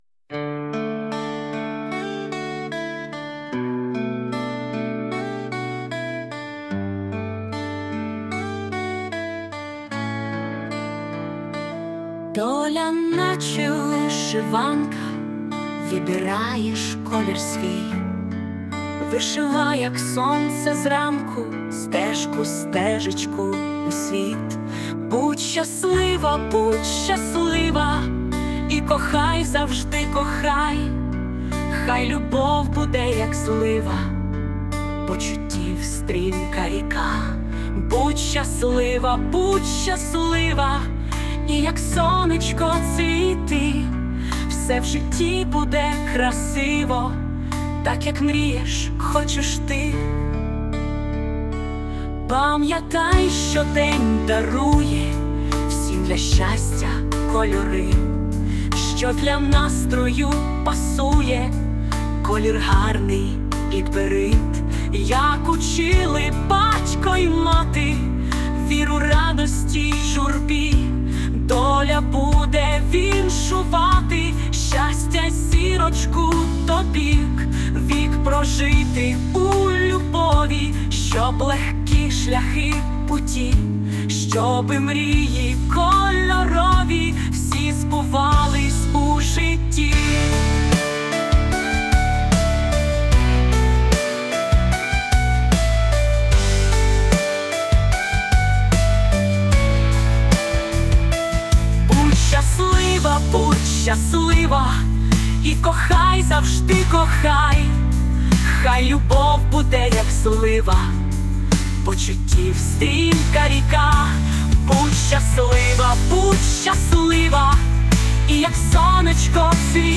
музичний супровід : ші суно
Дуже гарна пісня!